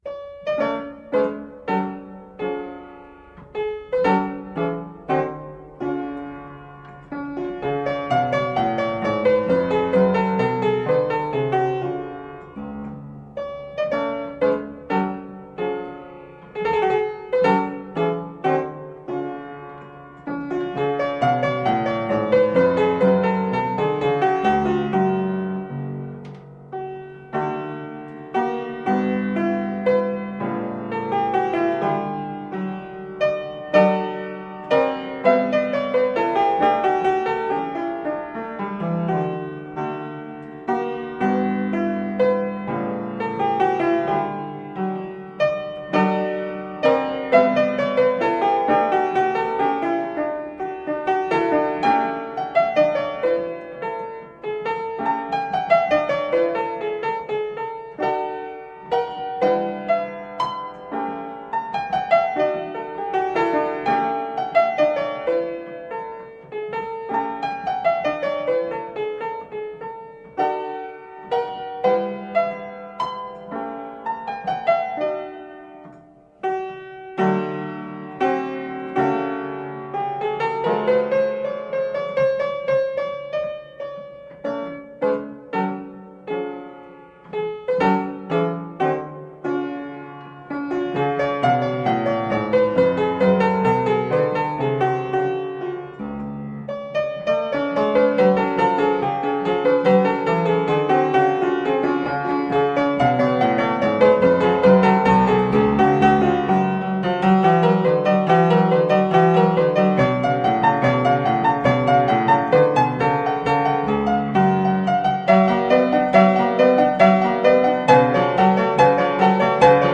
Marvelously played classical piano pieces.
Tagged as: Classical, Instrumental Classical, Piano